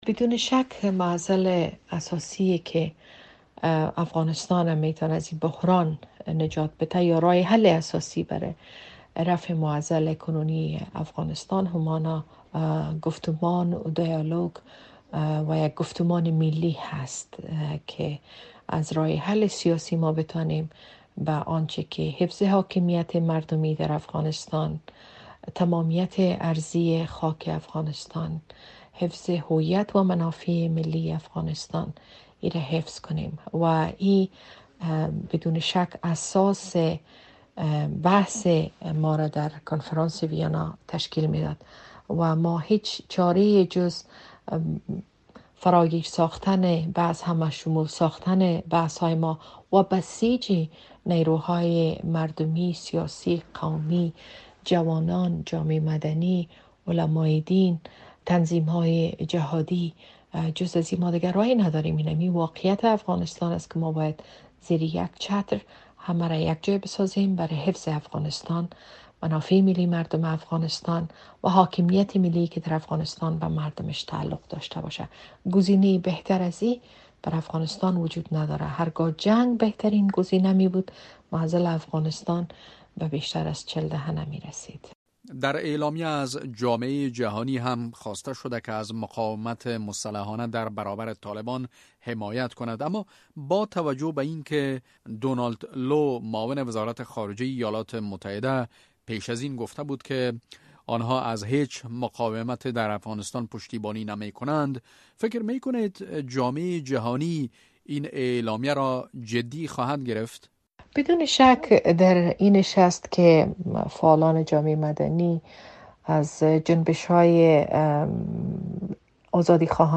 مصاحبه - صدا
شکریه بارکزی، عضو پیشین پارلمان نظام جمهوری مخلوع افغانستان